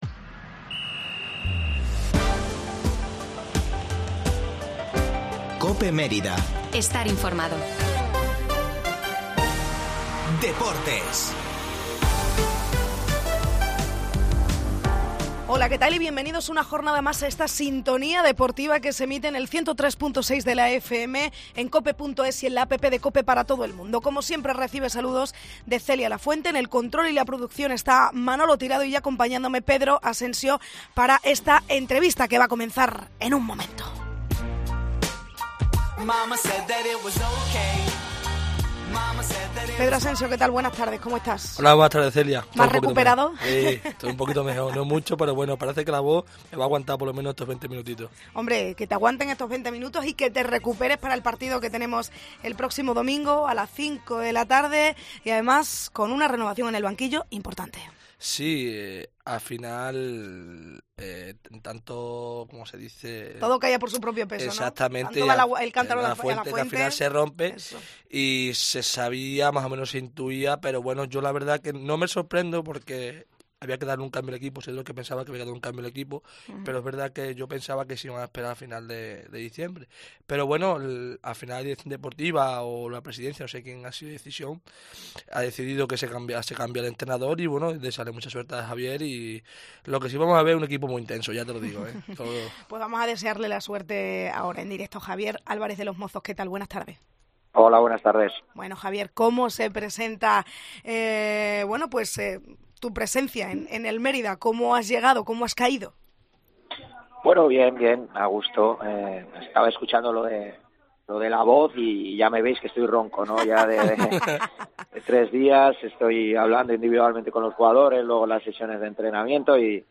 Tertulia del Mérida en COPE